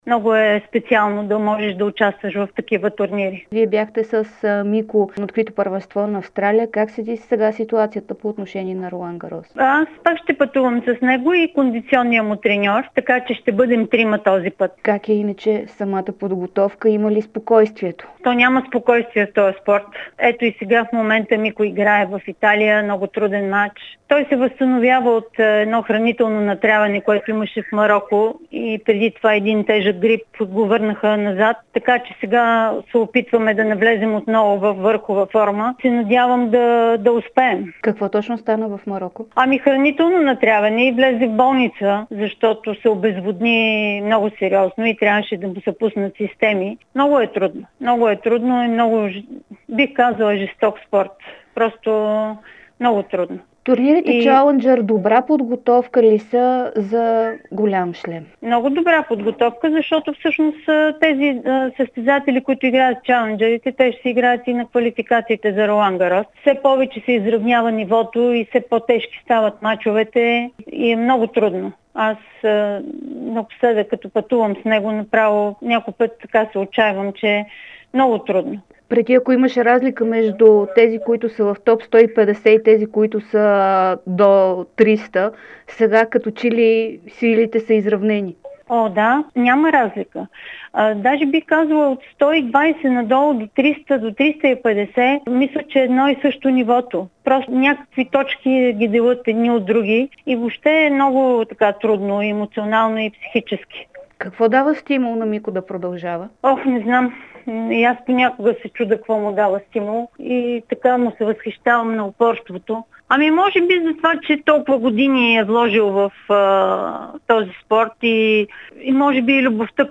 Дора Рангелова, която е капитан на България за „Били Джийн Кинг Къп“ и майка на Димитър Кузманов, говори ексклузивно пред dsport и Дарик радио относно подготовката на Мико за второто му участие в квалификациите за Ролан Гарос. Рангелова за пръв път разкри, че Кузманов е бил в болница в Мароко заради хранително натравяне.